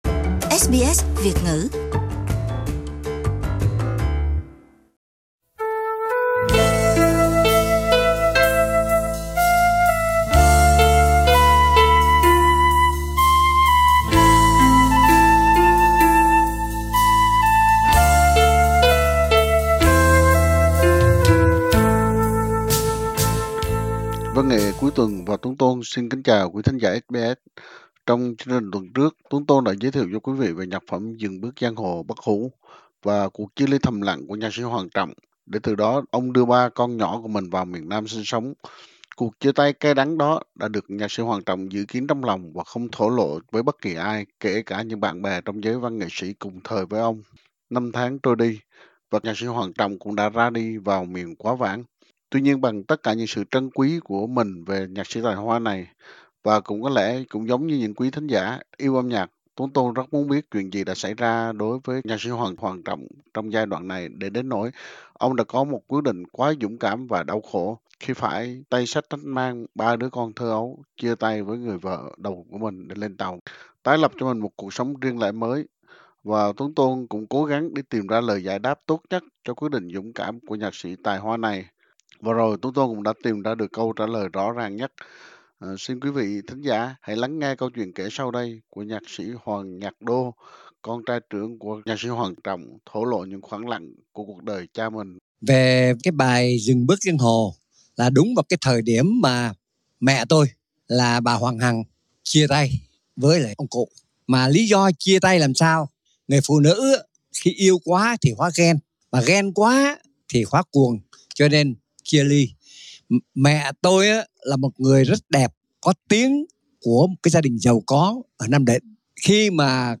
Do ảnh hửởng của thời âm nhạc tiền chiến, bản nhạc này được viết theo điệu Rumba và phảng phất chút giai điệu của âm nhạc Nhật bản.